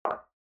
click-4.mp3